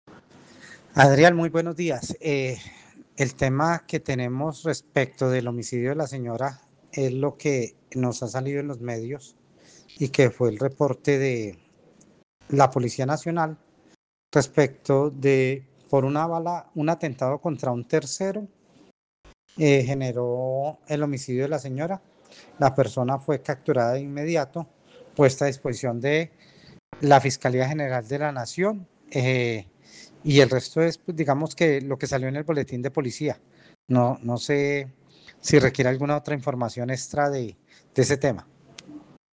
Carlos Arturo Ramírez, secretario de gobierno de Armenia